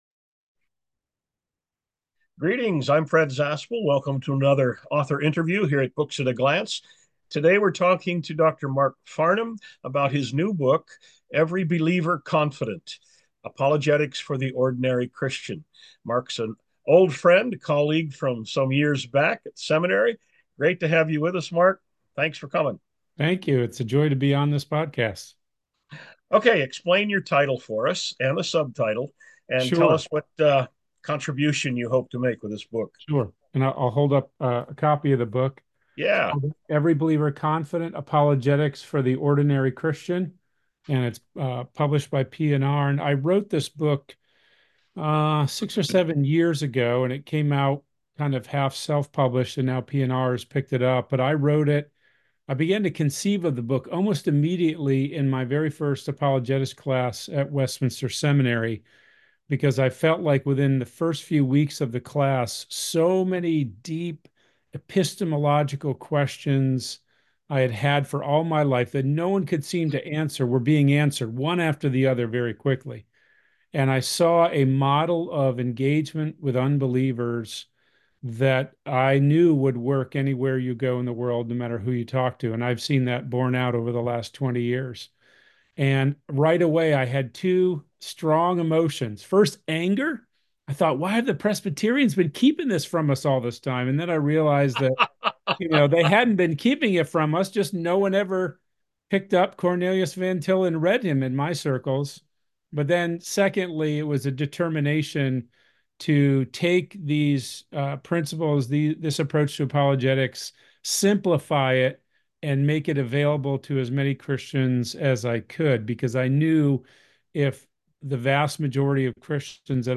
An Author Interview from Books At a Glance
Sample Audio Interview: